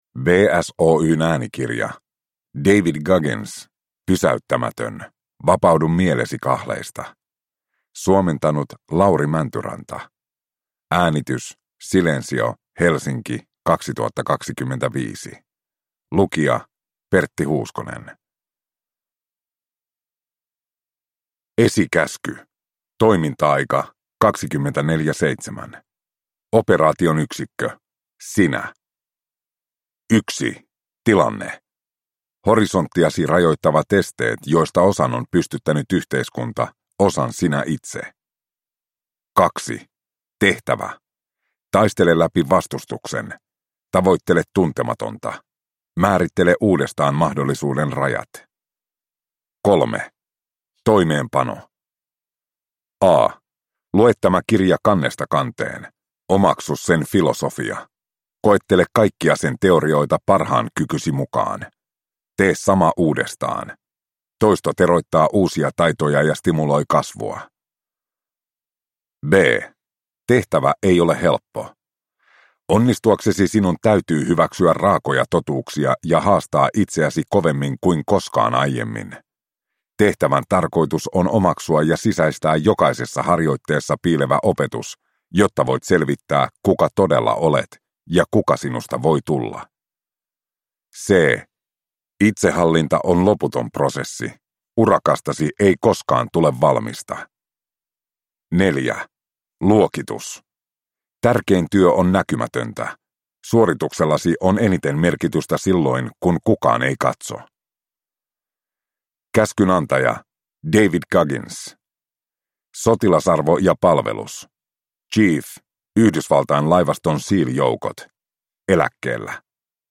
Pysäyttämätön – Ljudbok